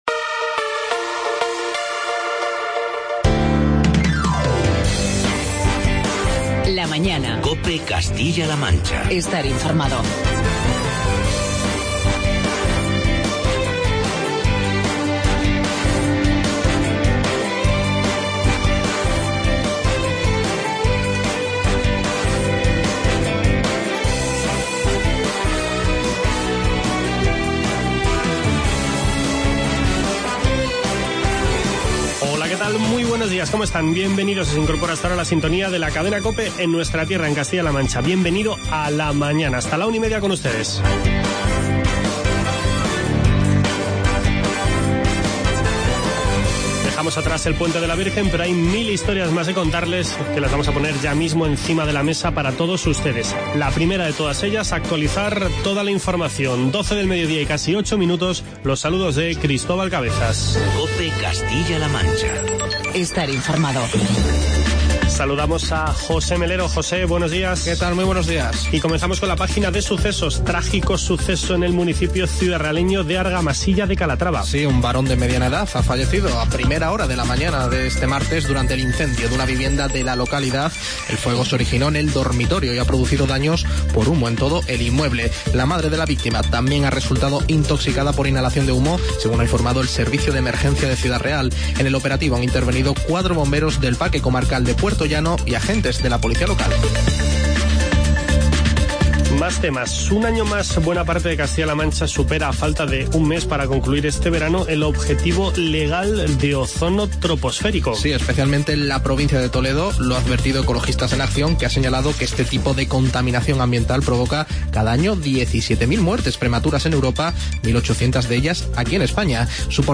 Charlamos con el consejero de Agricultura de la Junta de Comunidades, Francisco Martínez Arroyo.